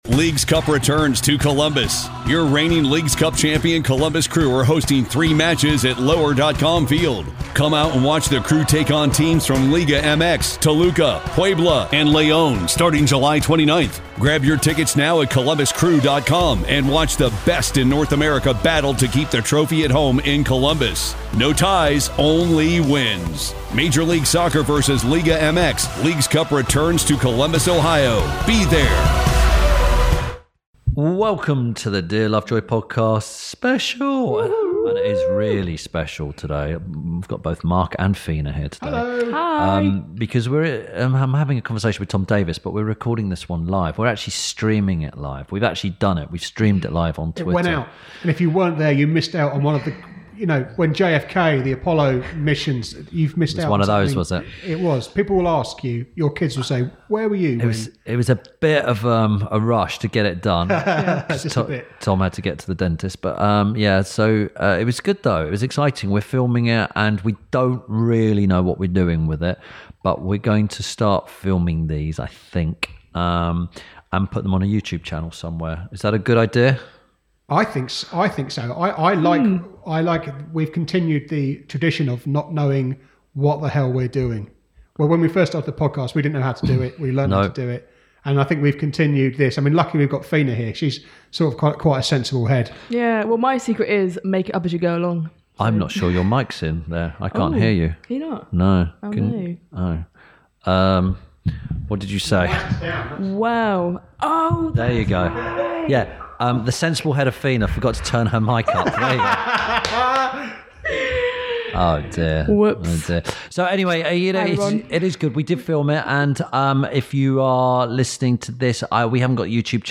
This week Tim Lovejoy talks to comedian, writer and actor Tom Davis. Whilst chatting about Tom’s new show (Action Team), they discuss how he started in comedy, life before he found success and how he learned to address his feelings.